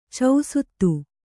♪ causuttu